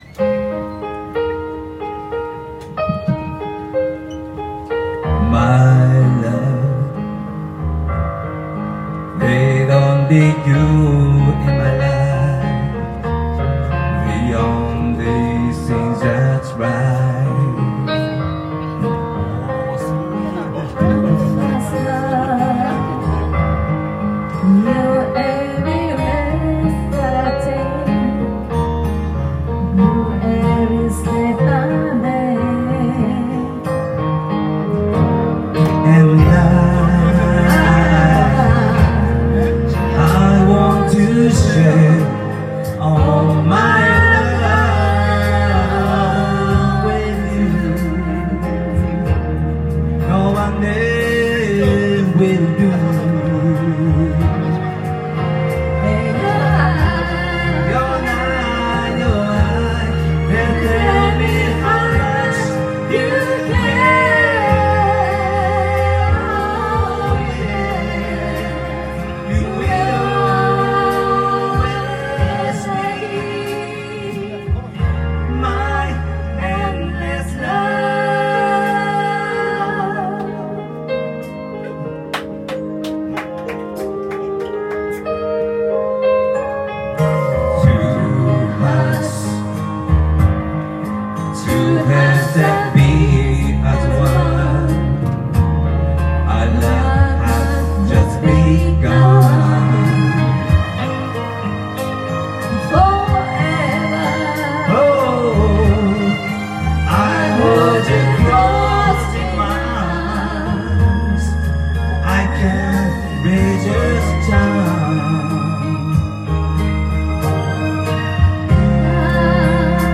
Duet & Chorus Night Vol. 21 TURN TABLE